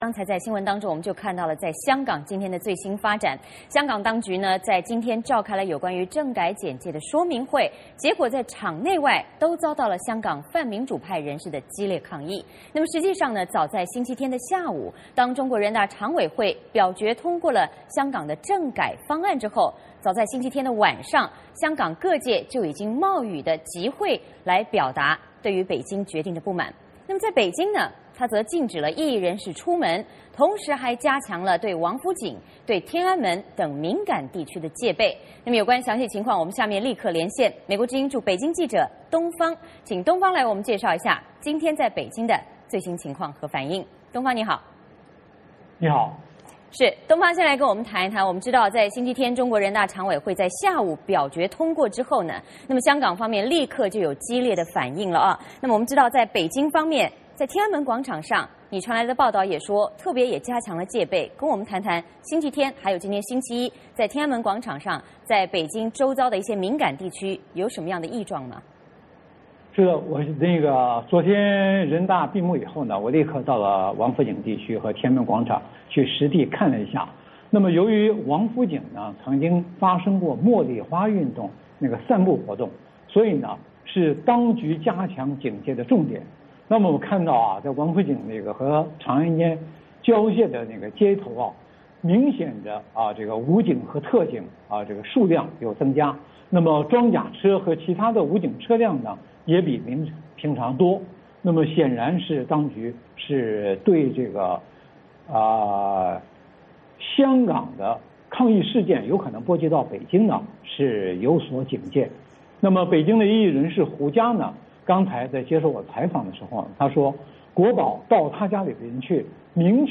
VOA连线：香港抗争激化，北京加强天安门戒备